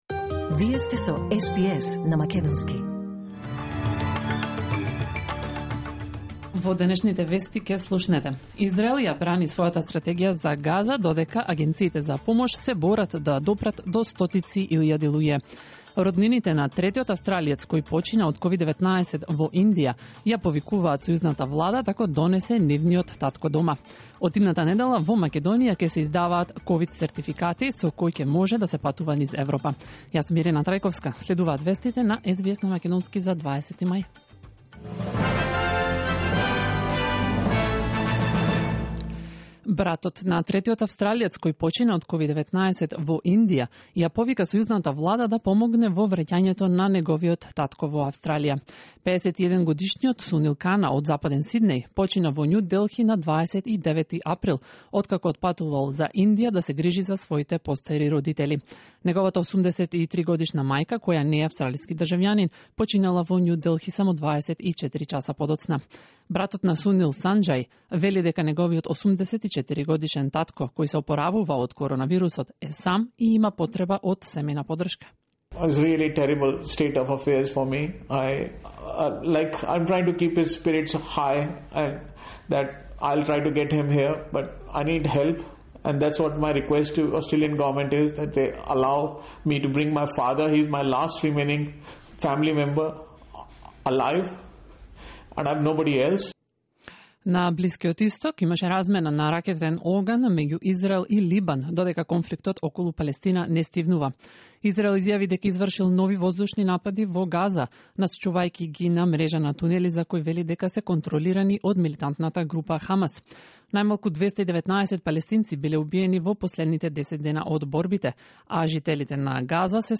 SBS News in Macedonian 20 May 2021